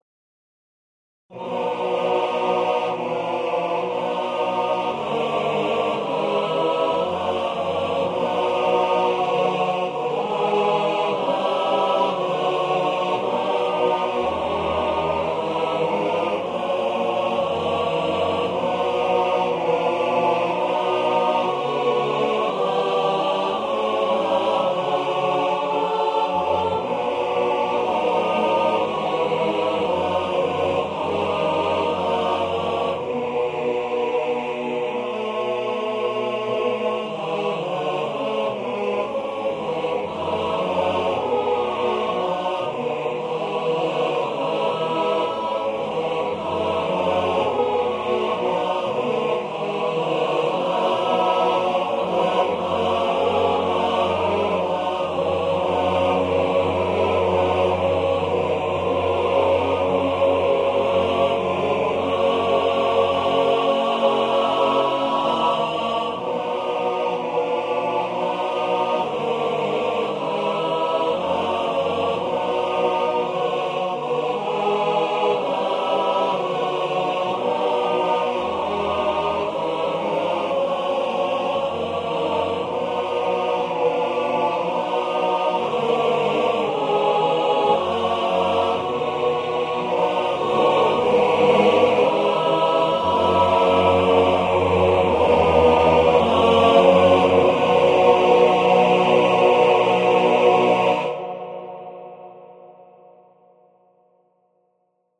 Besetzung: vierstimmiger Männerchor a cappella